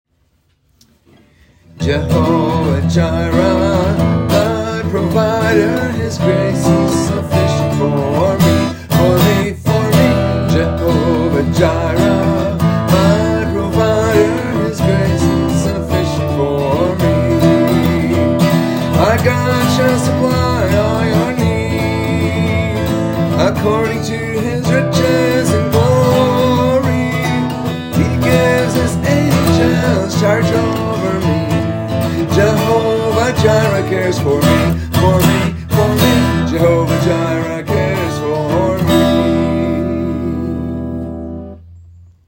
Transpose from E